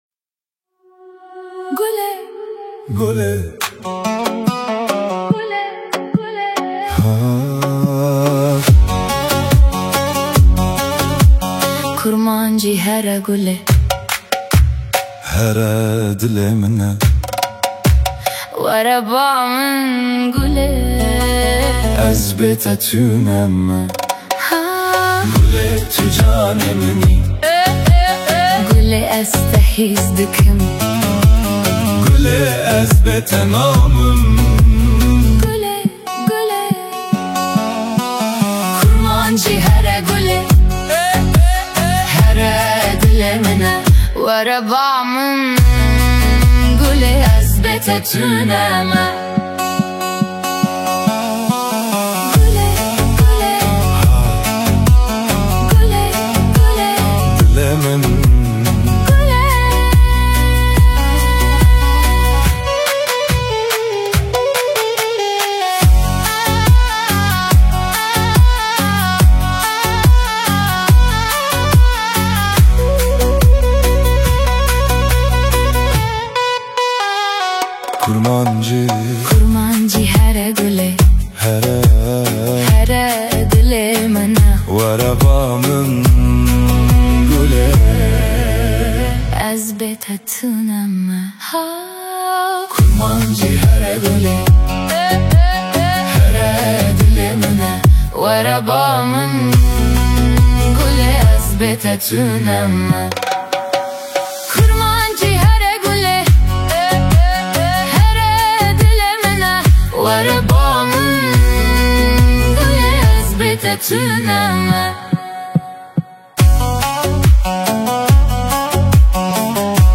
Зажигательный курдский дуэт
Курдский дуэт